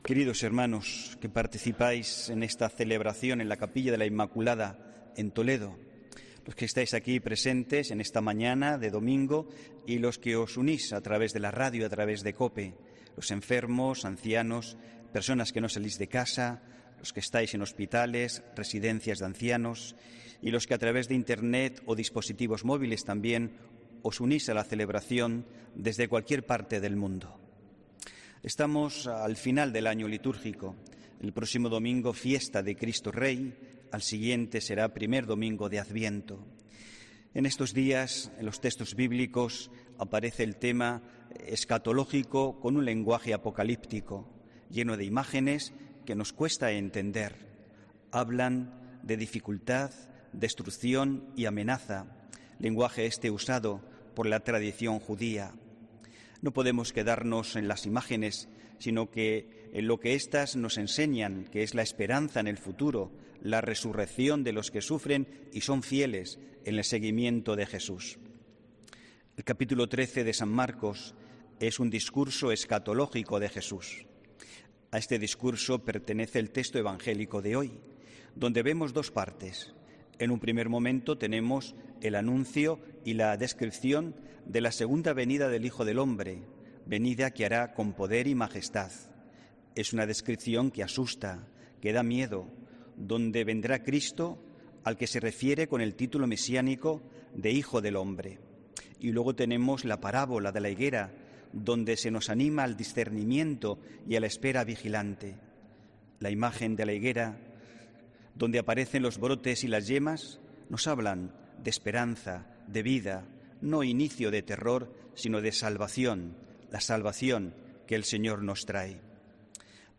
HOMILÍA 14 NOVIEMBRE 2021